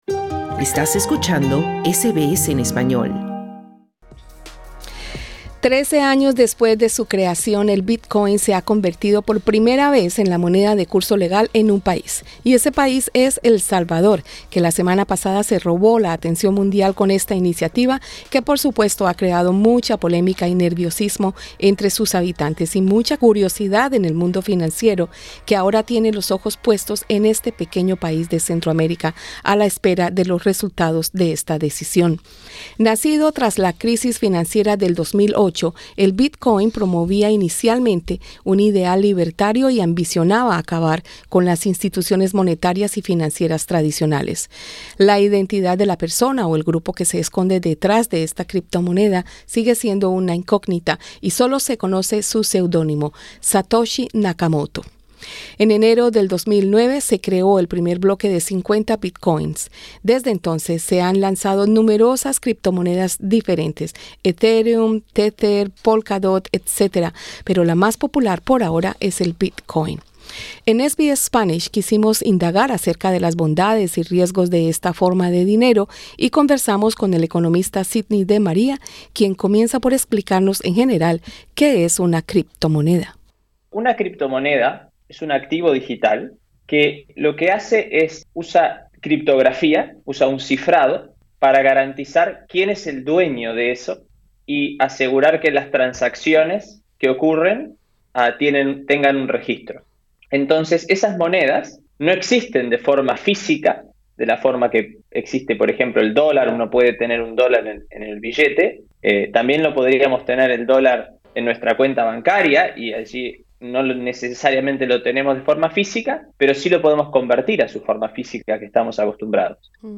En SBS spanish quisimos indagar acerca de las bondades y riesgos de esta forma de dinero y conversamos con el economista